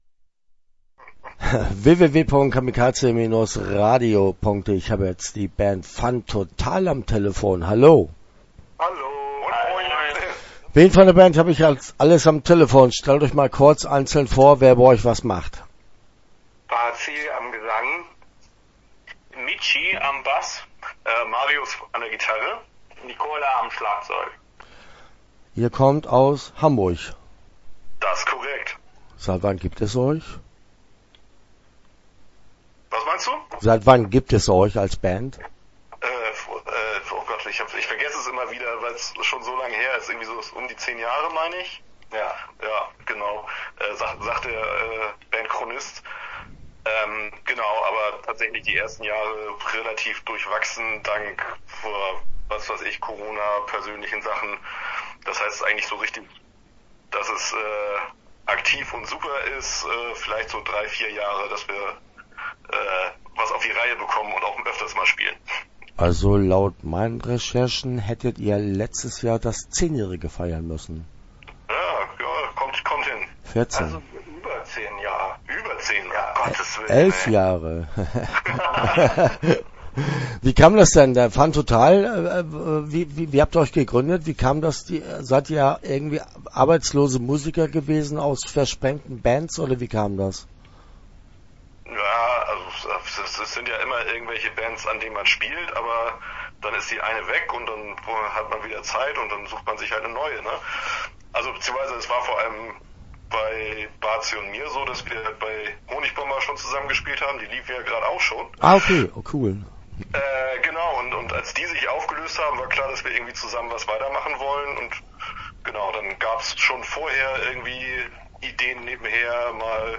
Start » Interviews » Fun Total